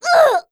cleric_f_voc_hit_b.wav